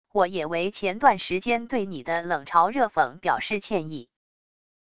我也为前段时间对你的冷嘲热讽表示歉意wav音频生成系统WAV Audio Player